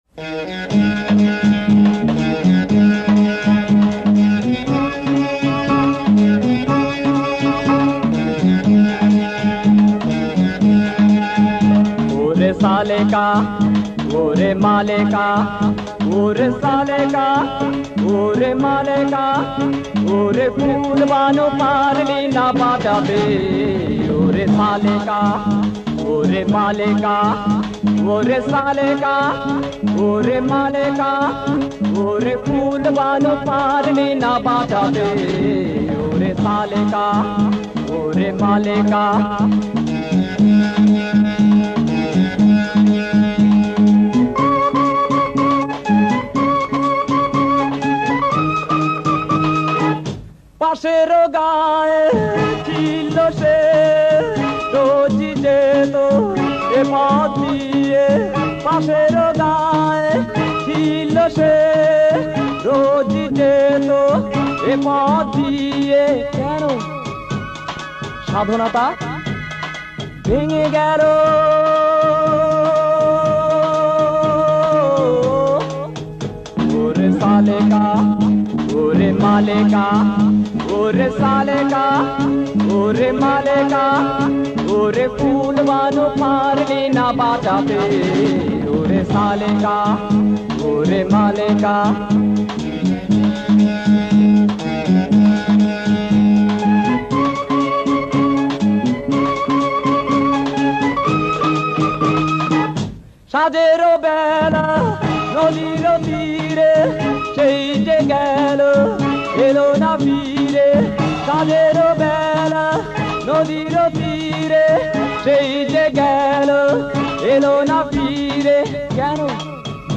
first pop band of Bangladesh